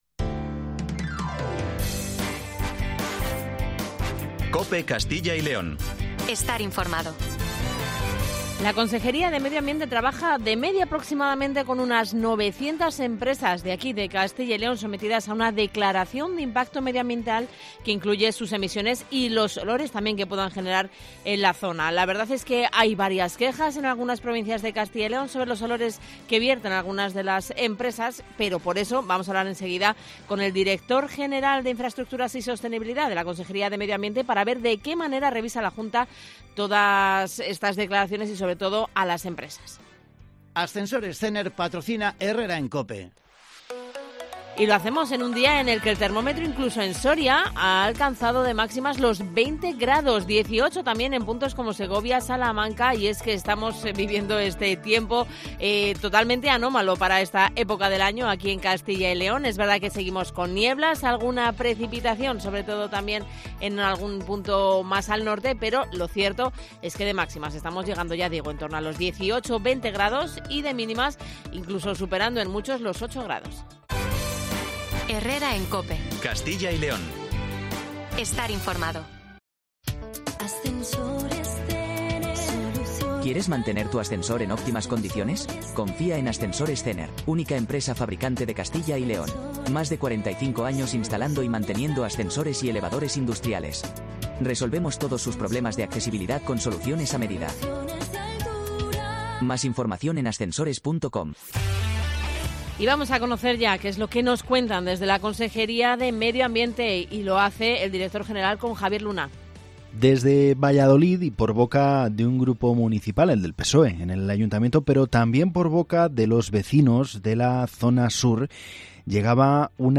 José Manuel Jiménez, Director General de Infraestraestructuras y Sostenibilidad nos habla de la normativa que regula los olores derivados de la actividad industrial.